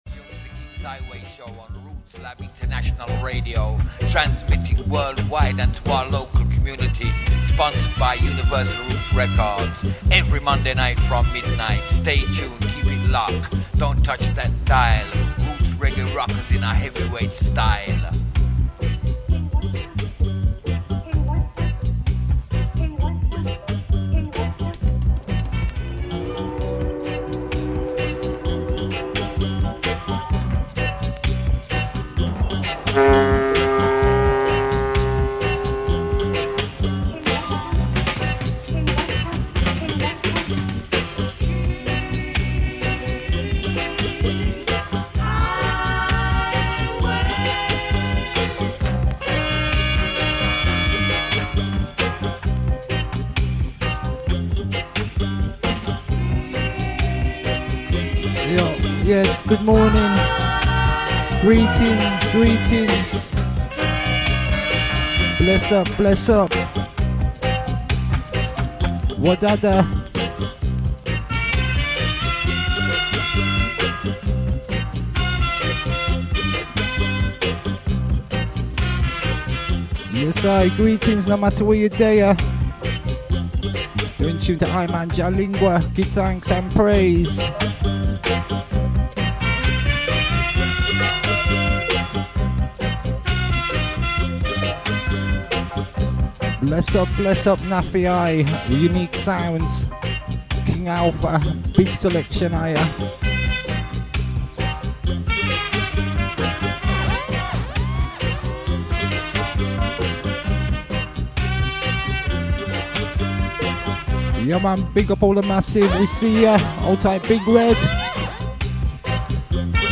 "Live in Session" Outa Brixton, London UK....